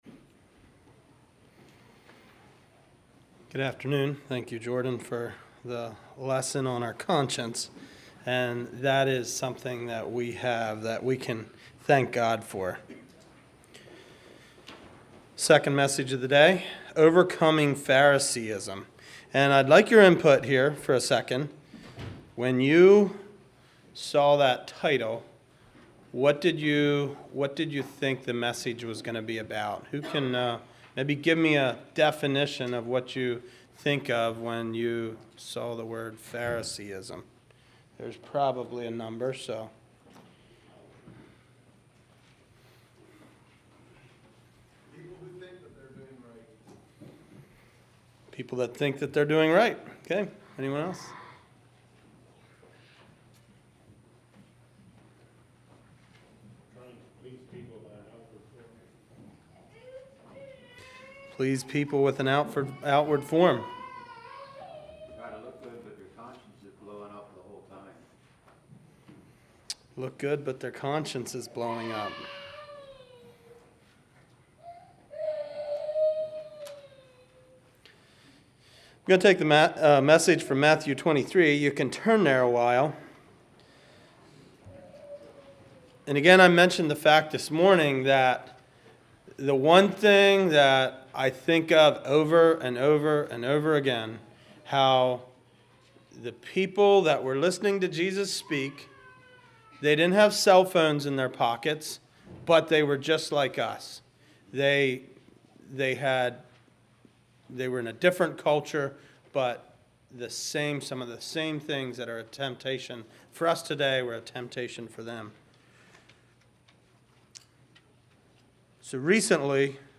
Sermons
Shirksville | Bible Conference 2025